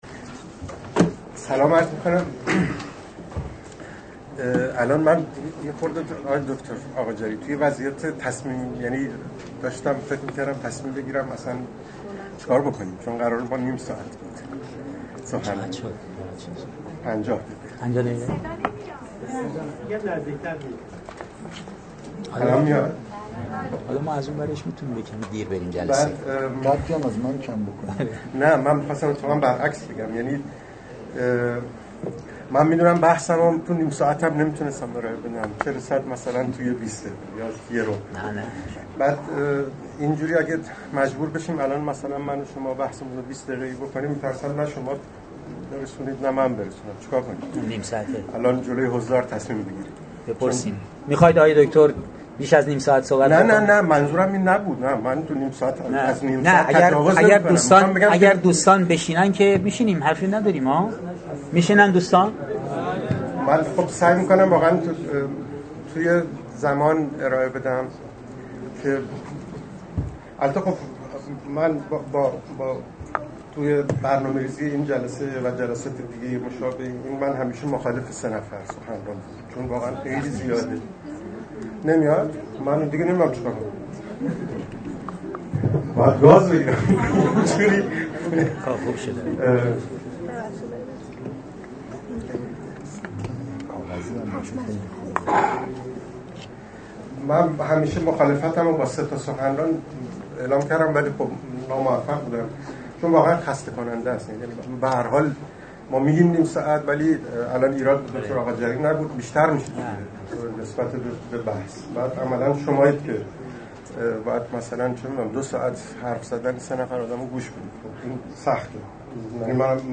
فایل زیر سخنرانی
در پژوهشگاه فرهنگ، هنر و ارتباطات برگزار شد.